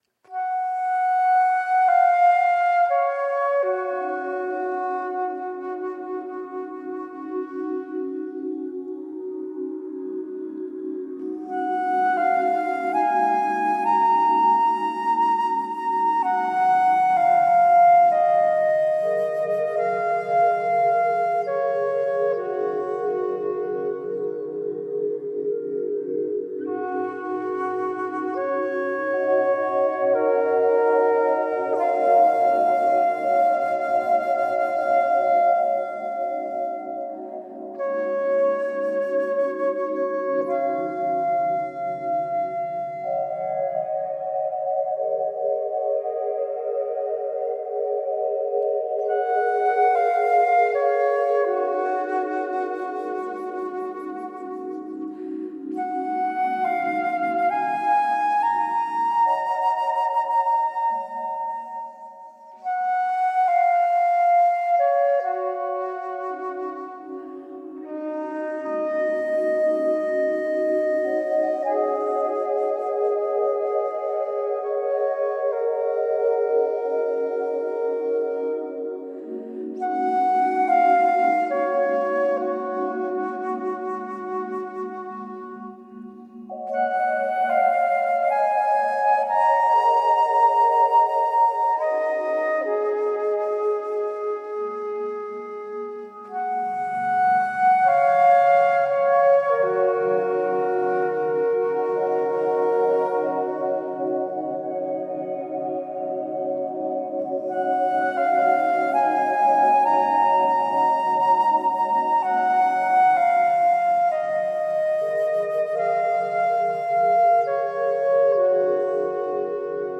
Thoughtful expressions through intelligent new age.